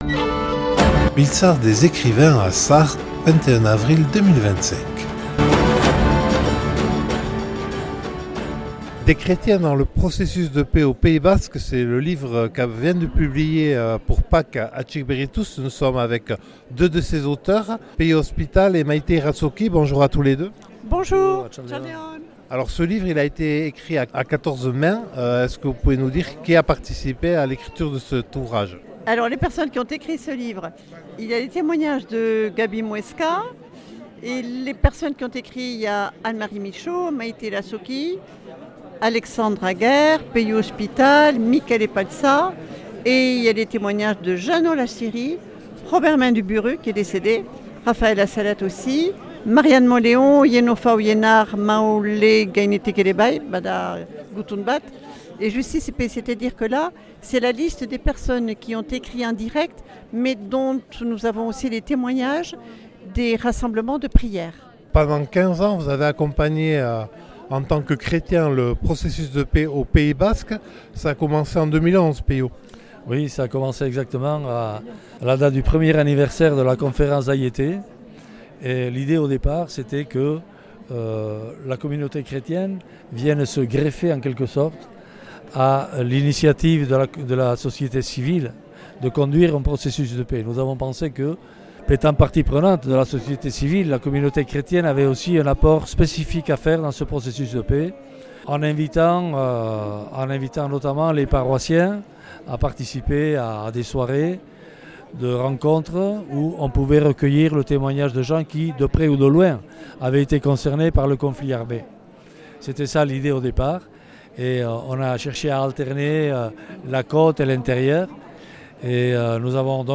Biltzar des écrivains à Sare - Editions Maiatz